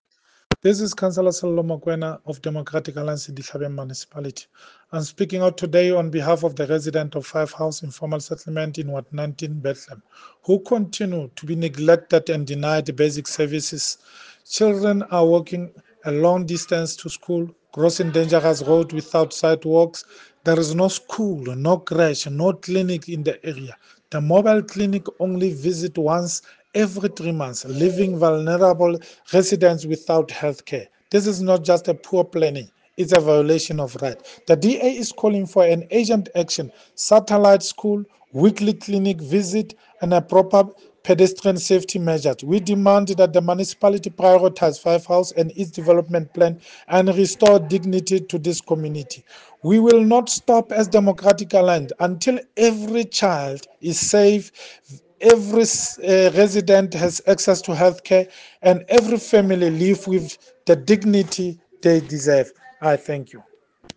Sesotho soundbites by Cllr Sello Makoena.